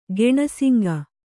♪ geṇasaŋgu